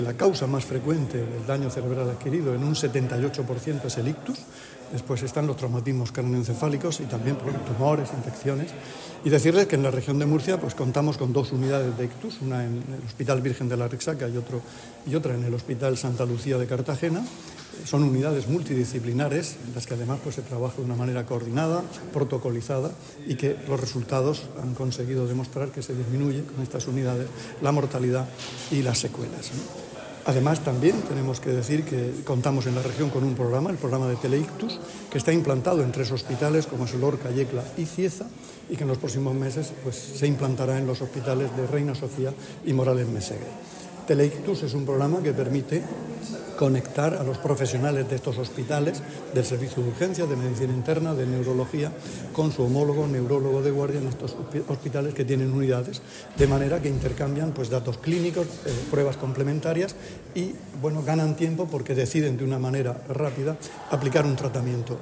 Sonido/ Declaraciones de consejero de Salud, Juan José Pedreño, sobre las causas del daño cerebral adquirido y su tratamiento en la Región [mp3]